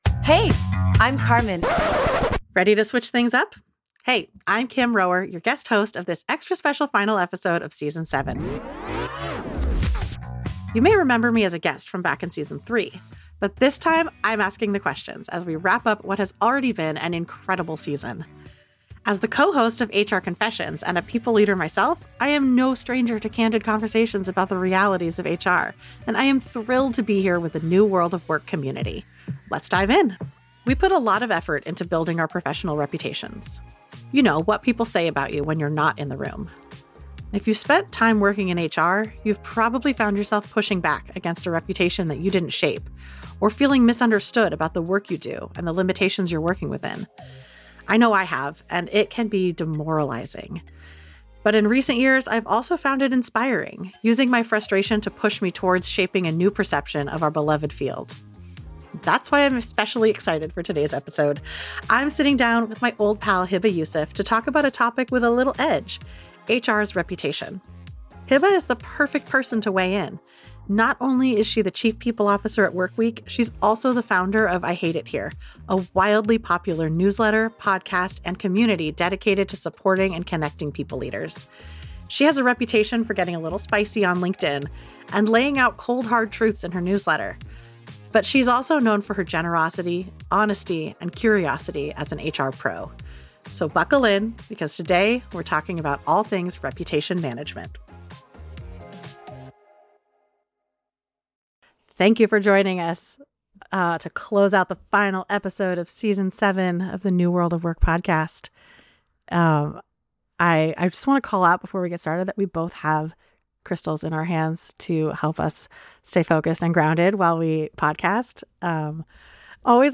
Plus, they dive into why CHROs might just be the best candidates for future CEOs. A candid, candor-filled conversation, this episode wraps up the season with a fresh take on how HR is evolving and how to make sure your reputation evolves with it.